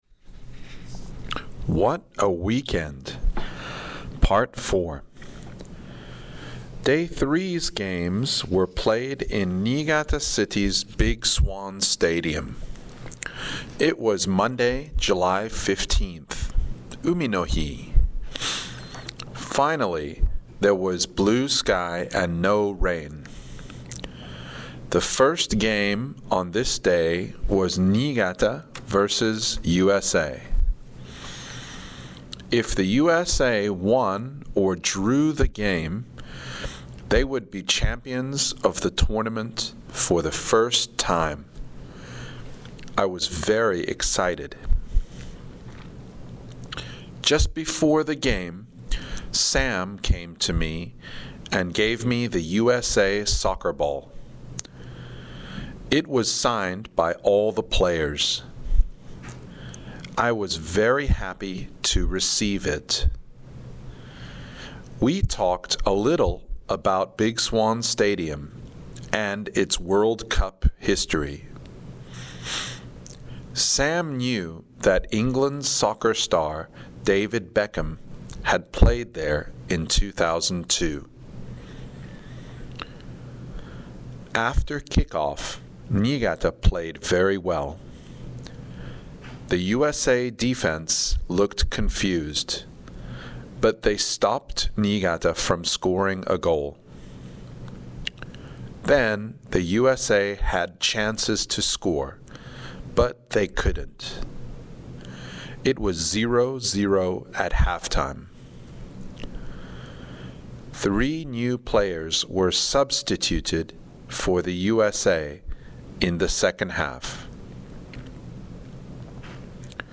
69 What a Weekend! (part 4) ＲＥＡＤＩＮＧ
(slow)